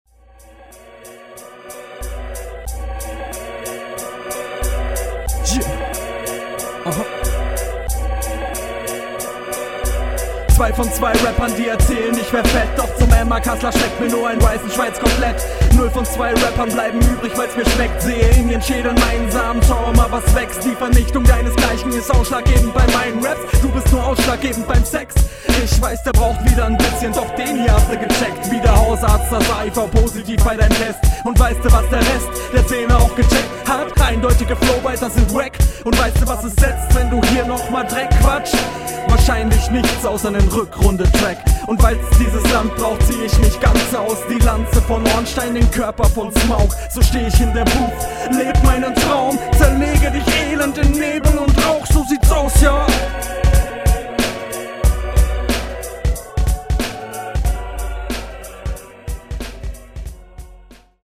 Beat ganz nach meinem Geschmack. Sound war hier gut und besser als in deiner RR1.